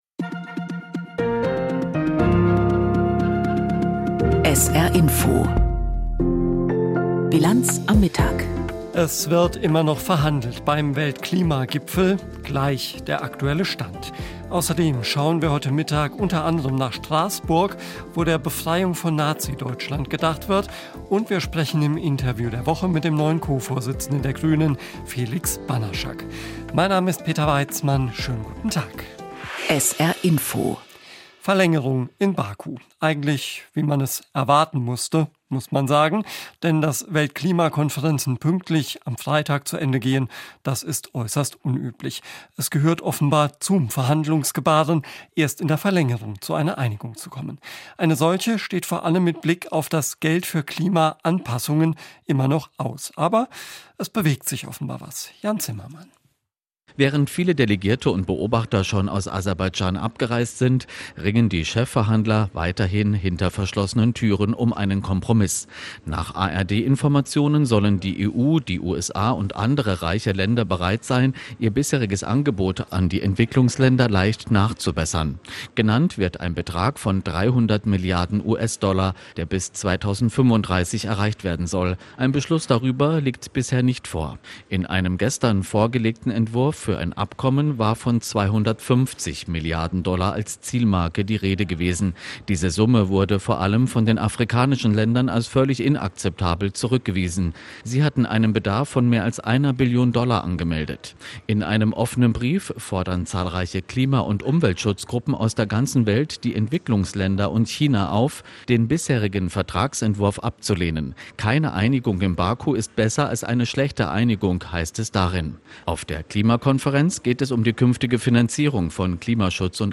Aktuelles und Hintergrnde zu Entwicklungen und Themen des Tages aus Politik, Wirtschaft, Kultur und Gesellschaft in Berichten und Kommentaren.
… continue reading 294 Episoden # Nachrichten # SR